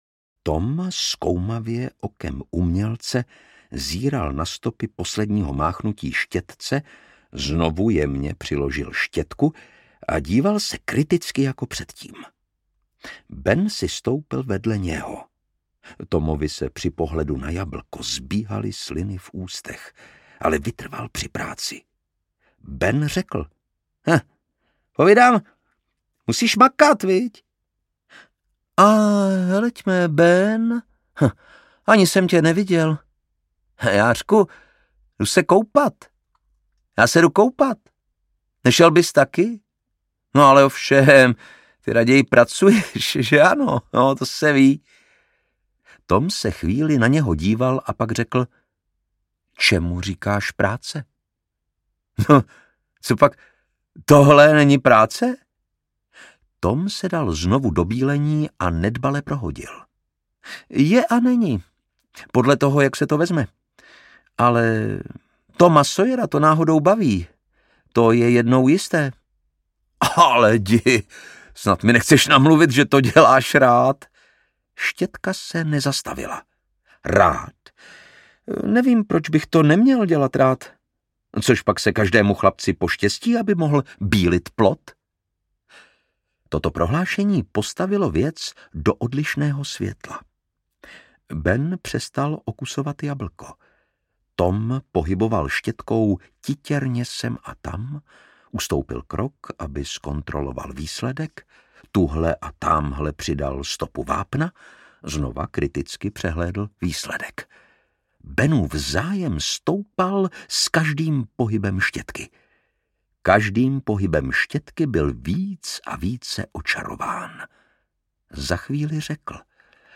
Dobrodružství Toma Sawyera audiokniha
Ukázka z knihy
Čte Lukáš Hlavica.
Vyrobilo studio Soundguru.